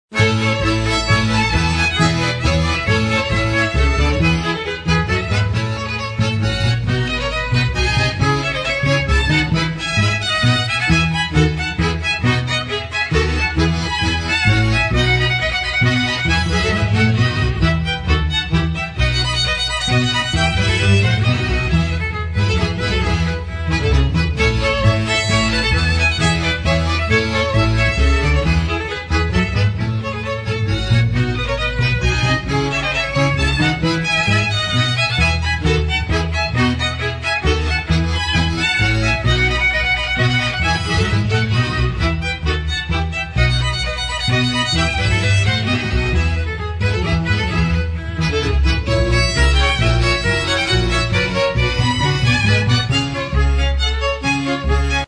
Ensemble of Instrumental Music